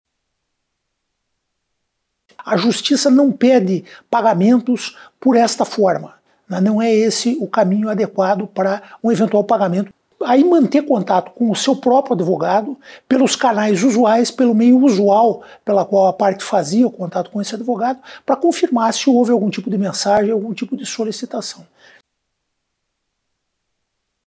O presidente do Tribunal Regional do Trabalho, desembargador Célio Waldraff, explicou como ocorre o início do contato do golpista com a vítima.